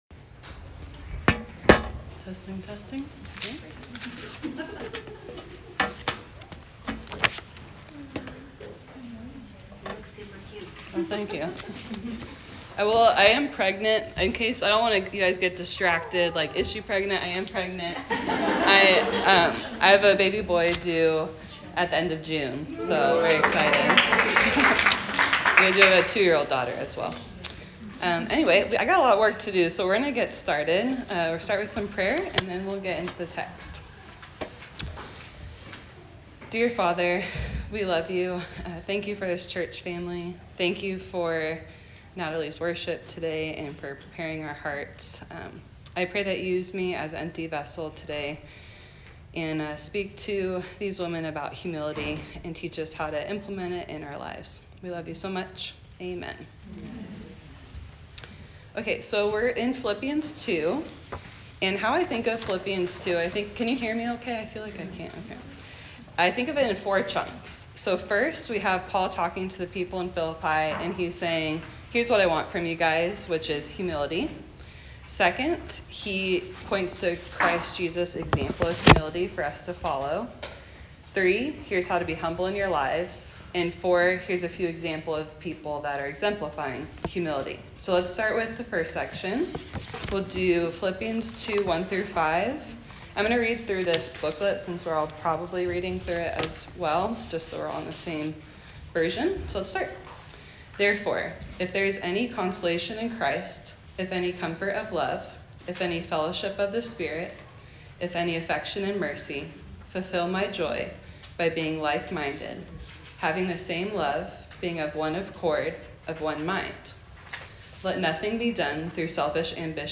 Teaching on Philippians 2
This week, I taught at our women’s Bible study on Philippians 2.